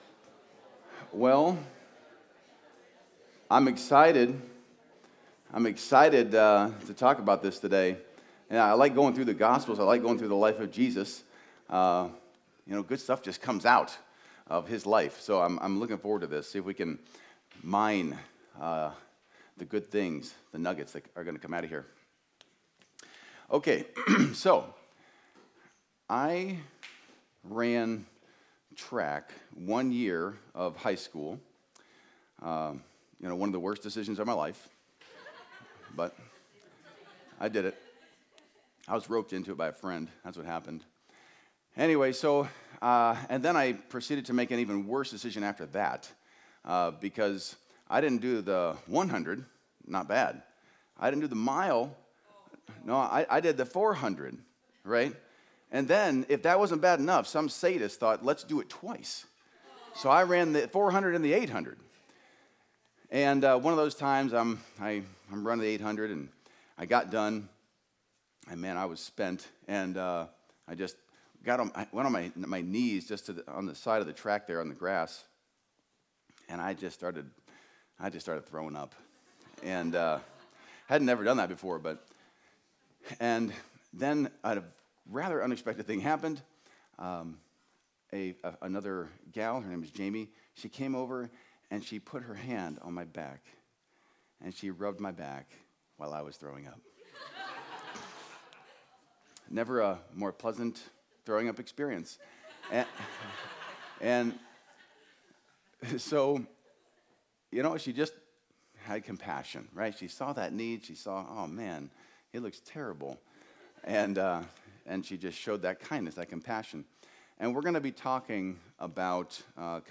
Video Audio Download Audio Home Resources Sermons World Changer: The Time is Fulfilled Apr 26 World Changer: The Time is Fulfilled Jesus begins His ministry by calling the disciples with extraordinary authority and by amazing miracles establishing who He is.